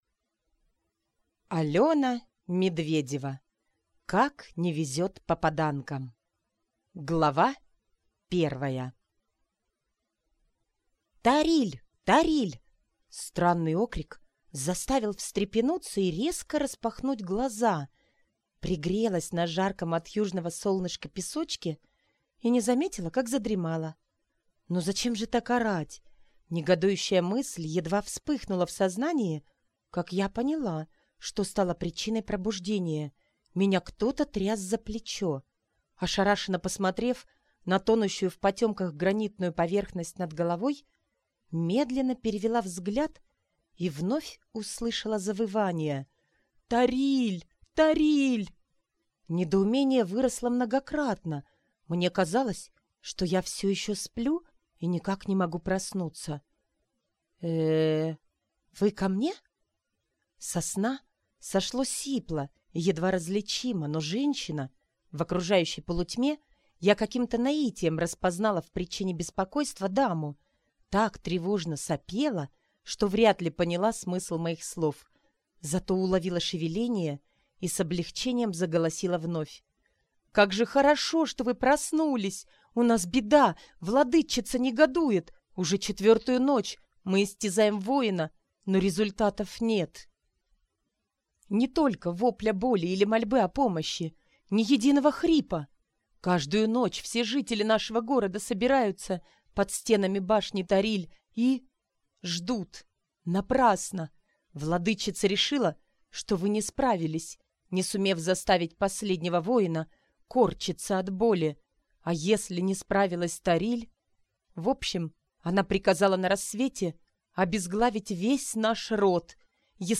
Аудиокнига Как не везет попаданкам!